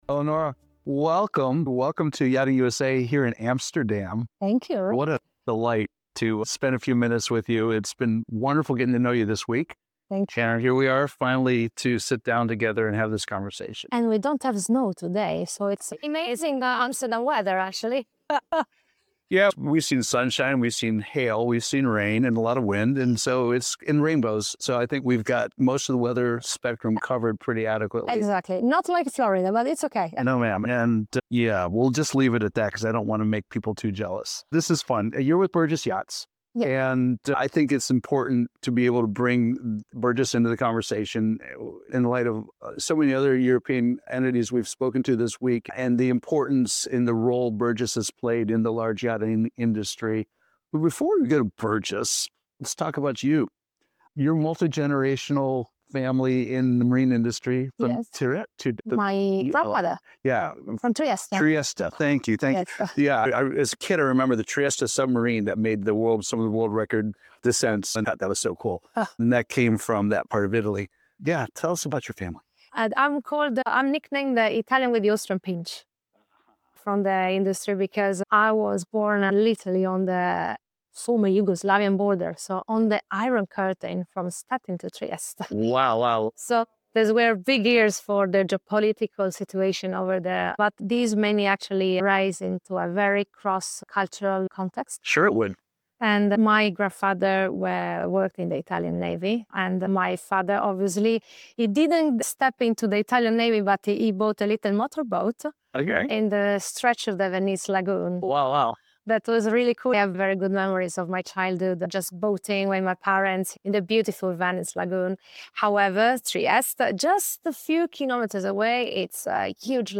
Recorded at Metstrade in Amsterdam
Learn about biofuels, methanol, and collaborative advancements in fuel cell technology, as well as the evolving demands of informed yacht owners. Don’t miss this inspiring conversation about trust, reputation, and the future of yachting!